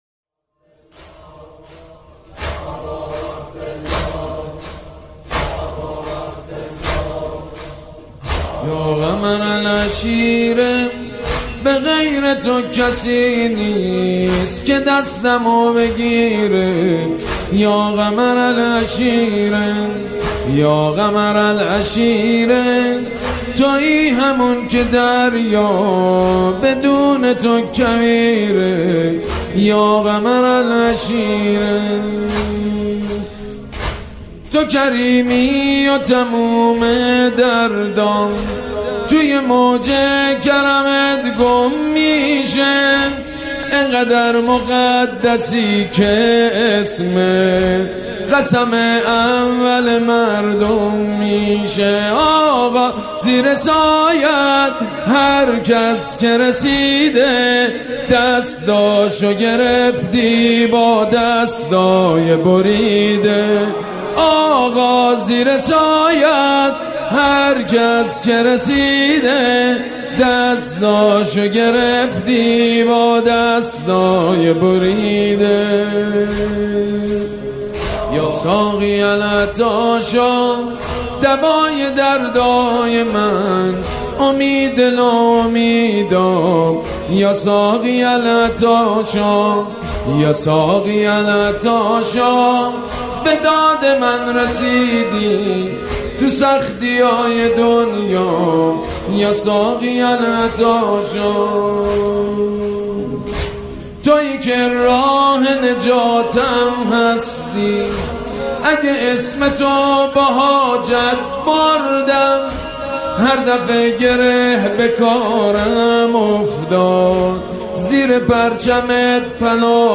مداحی دلنشین و شنیدنی